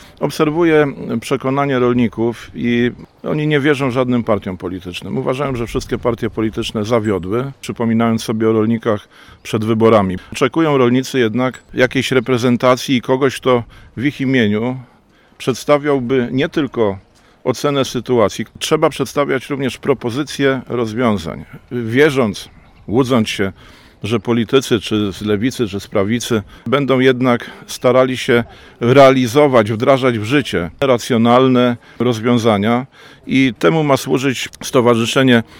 Spotkanie w Tychowie. Ardanowski o problemach i wyzwaniach polskiej wsi